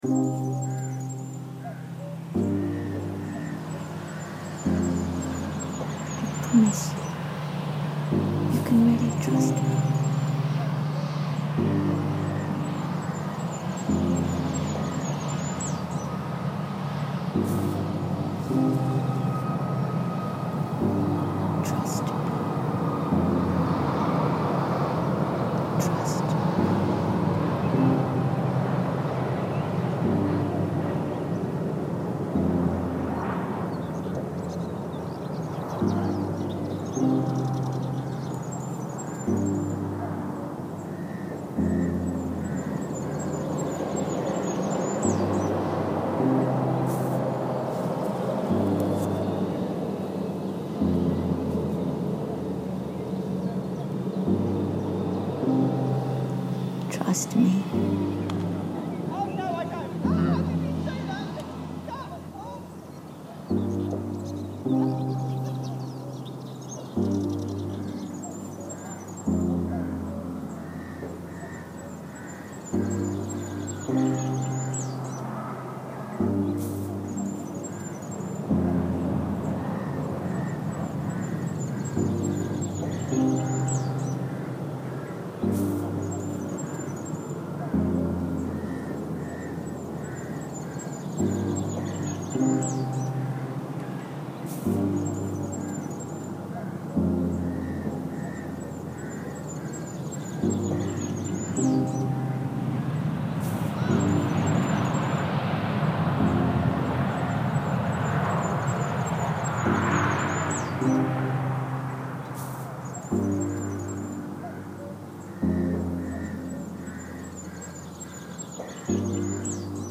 Gatwick Airport runway reimagined